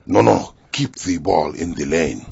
zen_nonokeepballinlane.wav